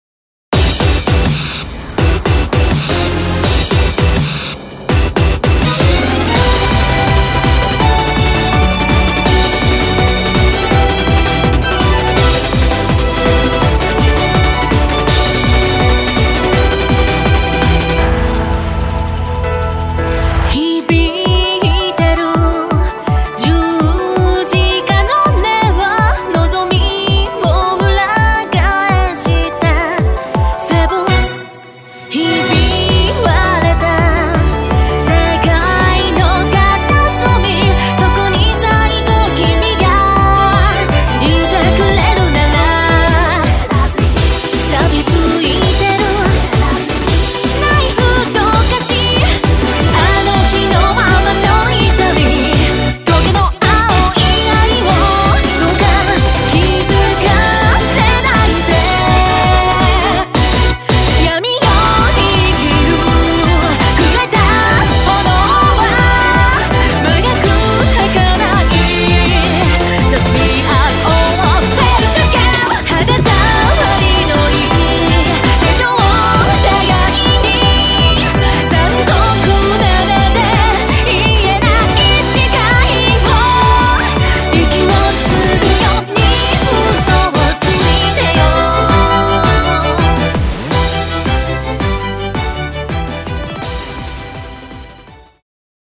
ヴォーカル